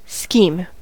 scheme: Wikimedia Commons US English Pronunciations
En-us-scheme.WAV